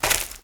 High Quality Footsteps / Leaves
STEPS Leaves, Walk 23.wav